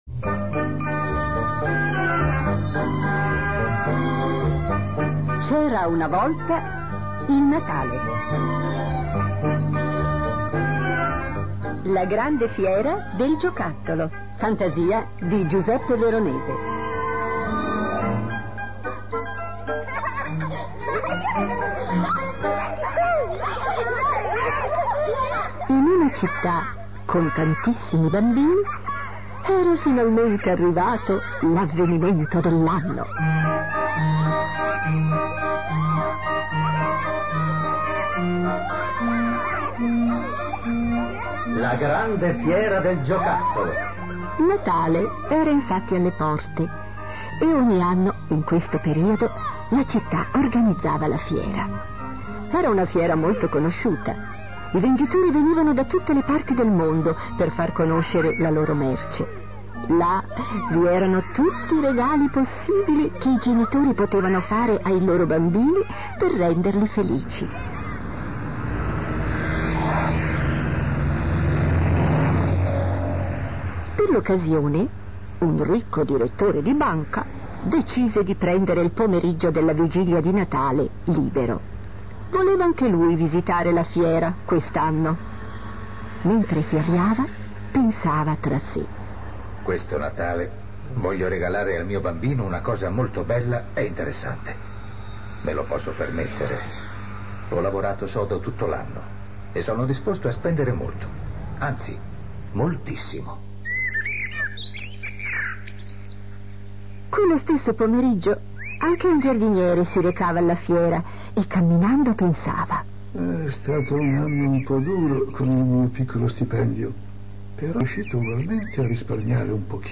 Grazie mille a tutti gli interpreti spettacolari:
Ringrazio la Radio della Svizzera Italiana per la bellissima interpretazione!